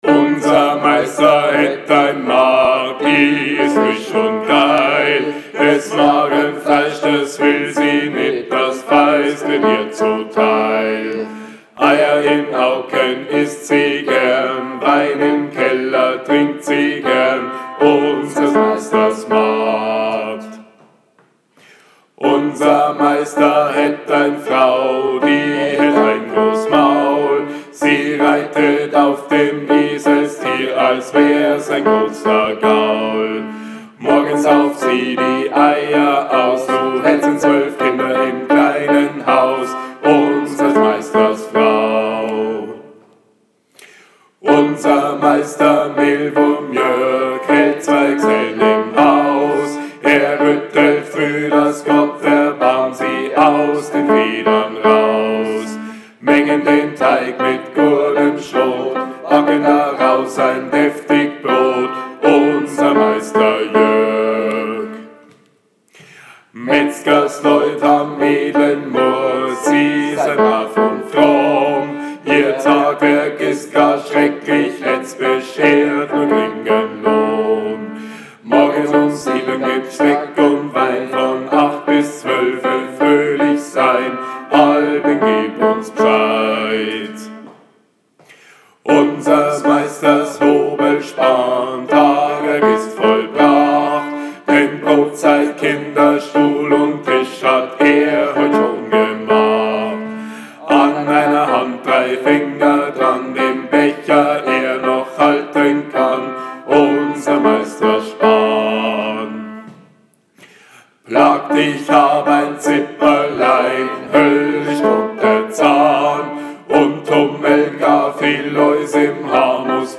38 Unser meister magd BASS.mp3